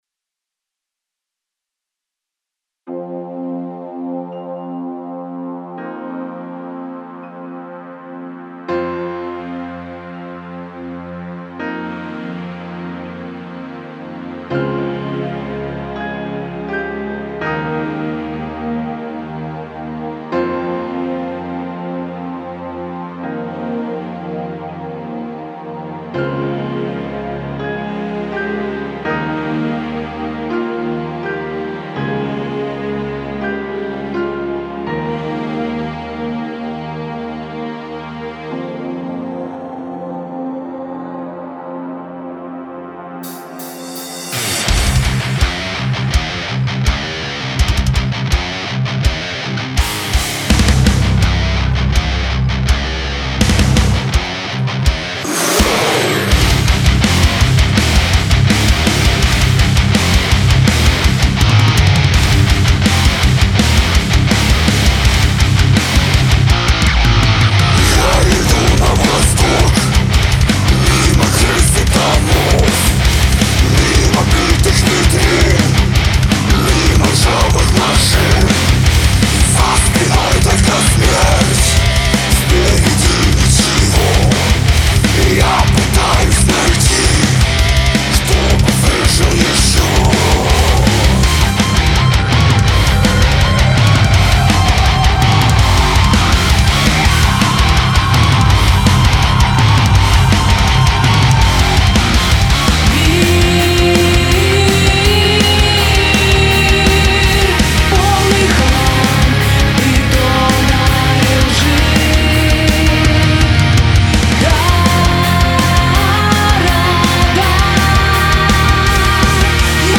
Стиль: Experimental/Industrial/Modern Metal